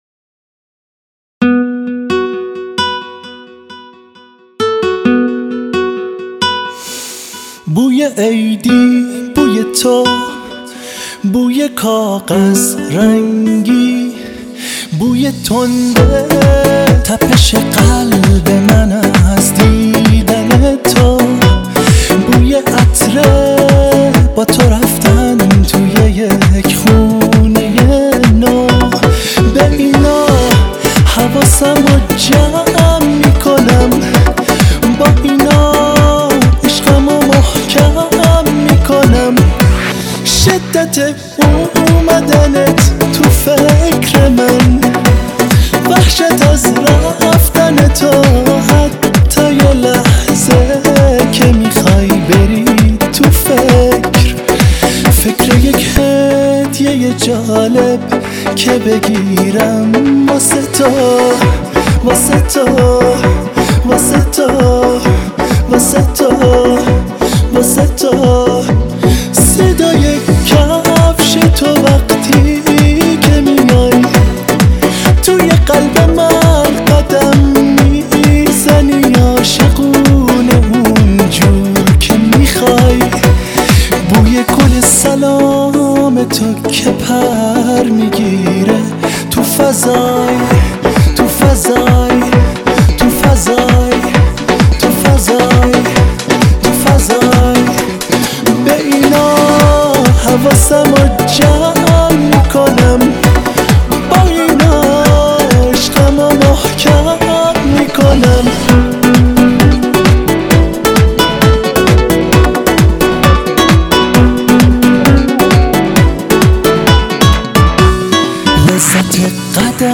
хонандаи эронӣ
мусиқӣ